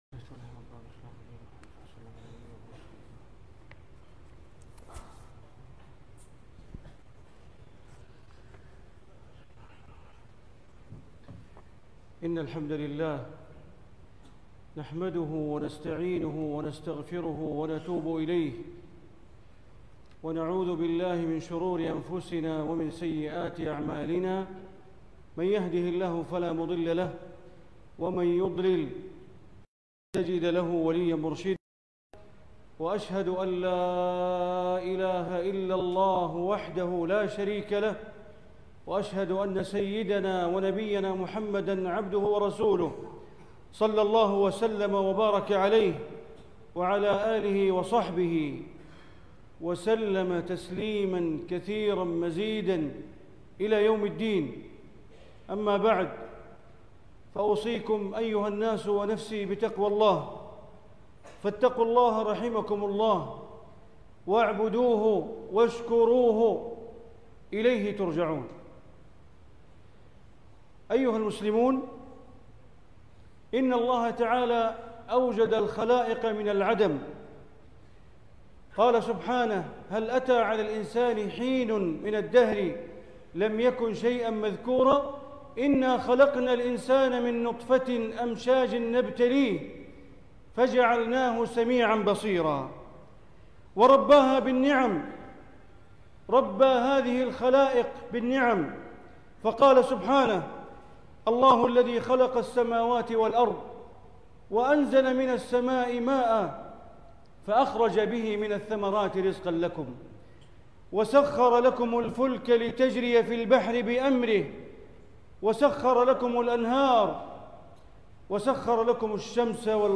خطبة الجمعة للشيخ بندر بليلة 12 صفر 1446هـ من مملكة كمبوديا > زيارة الشيخ بندر بليلة الى دولة كمبوديا > المزيد - تلاوات بندر بليلة